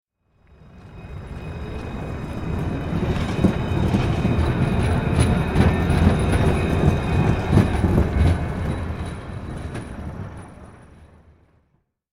دانلود آهنگ قطار 12 از افکت صوتی حمل و نقل
جلوه های صوتی
دانلود صدای قطار 12 از ساعد نیوز با لینک مستقیم و کیفیت بالا